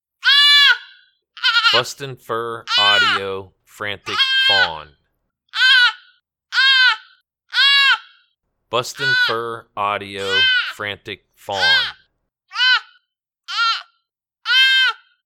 Whitetail Fawn in distress.